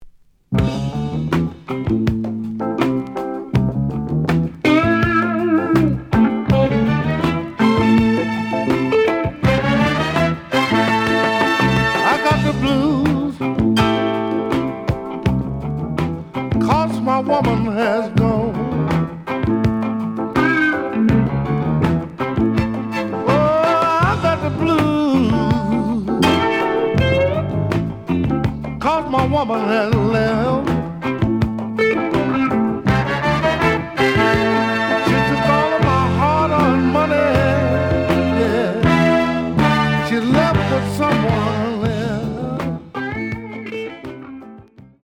The audio sample is recorded from the actual item.
●Genre: Blues
Some click noise on B side due to scratches.